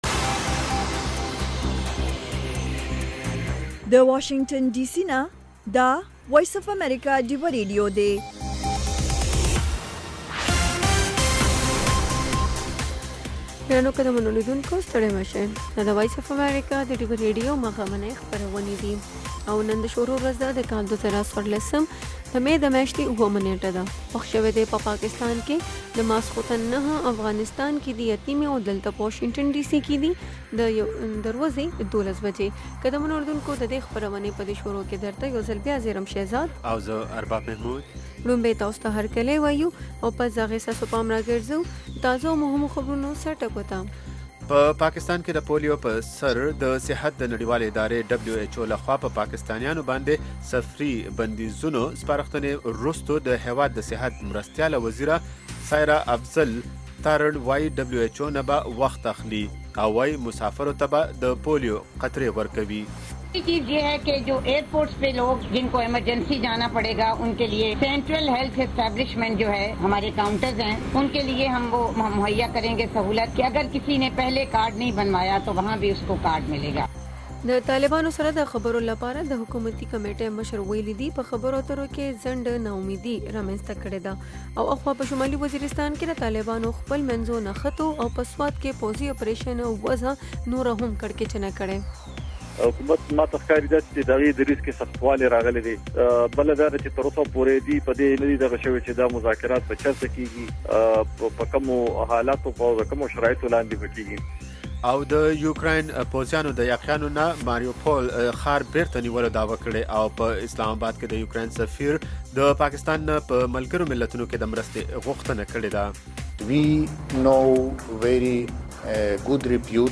دا یو ساعته خپرونه خونده ورې سندرې لري میلمانه یې اکثره سندرغاړي، لیکوالان، شاعران او هنرمندان وي.